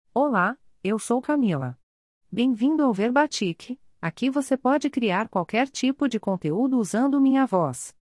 CamilaFemale Brazilian Portuguese AI voice
Camila is a female AI voice for Brazilian Portuguese.
Voice sample
Listen to Camila's female Brazilian Portuguese voice.
Camila delivers clear pronunciation with authentic Brazilian Portuguese intonation, making your content sound professionally produced.